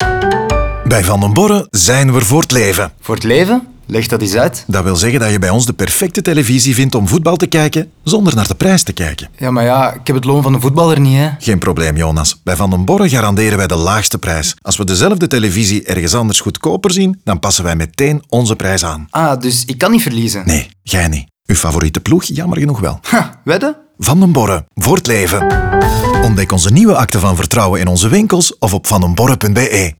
Zo gaat de voice-over telkens in dialoog met verschillende klanten en helpt hij hen met al hun vragen.
Last but not least kreeg ook het soundlogo een lichte make-over.